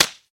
Slap5.wav